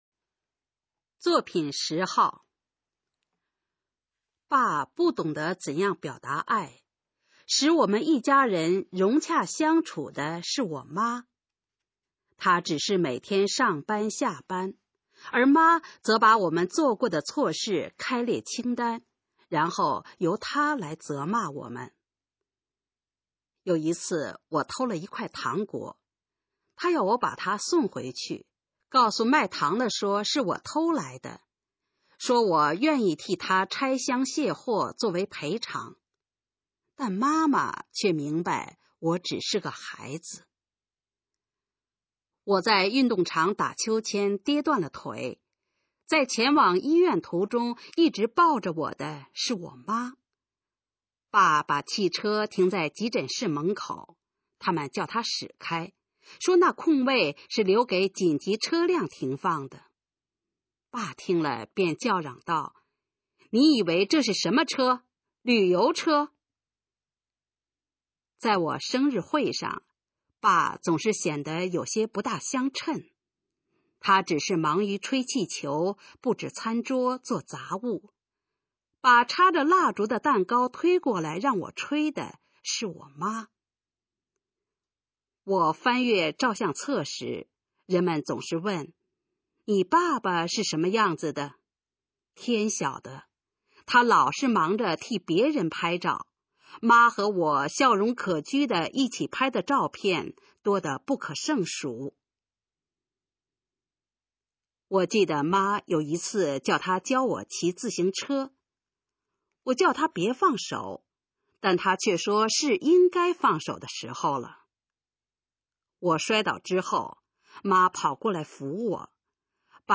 首页 视听 学说普通话 作品朗读（新大纲）
《父亲的爱》示范朗读_水平测试（等级考试）用60篇朗读作品范读